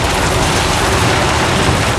rr3-assets/files/.depot/audio/sfx/tyre_surface/tyres_dirt_roll.wav
tyres_dirt_roll.wav